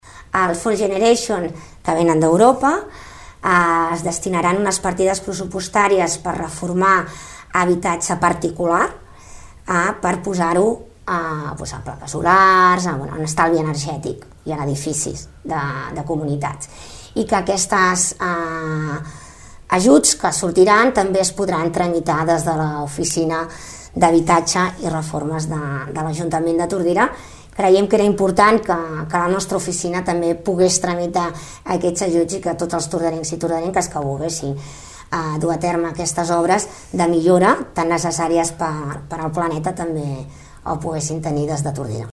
Ho explicava la regidora d’habitatge, Toñi Garcia.